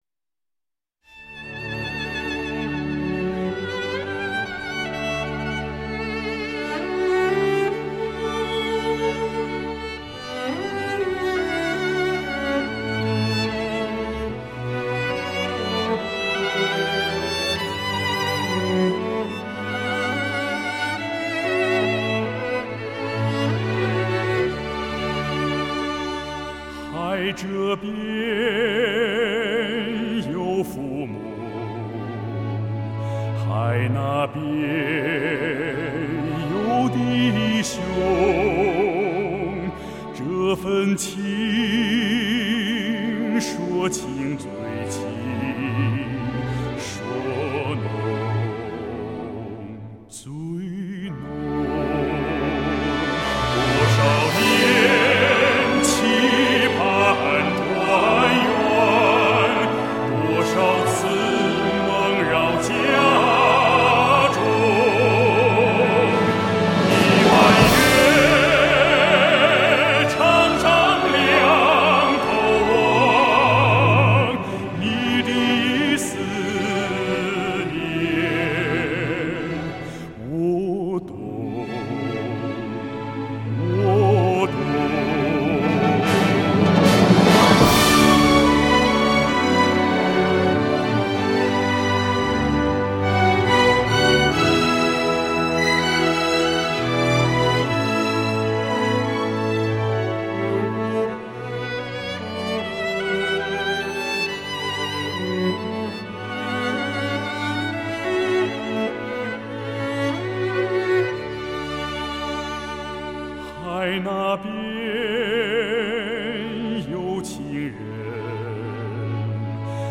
原唱是D调，俺实在没有中低音的唱功，只好升了三个key到F调才能唱下来。
因为伴奏中有人声合唱，升调后听上去有点儿失真，罪过罪过。...